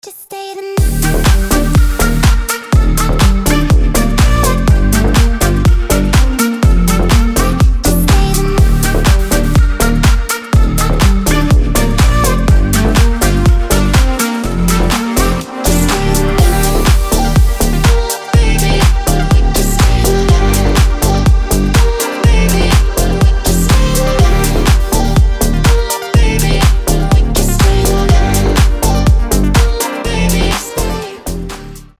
• Качество: 320, Stereo
ритмичные
deep house
милые
приятные
забавный голос
house
Разве не прелестная электронная музычка)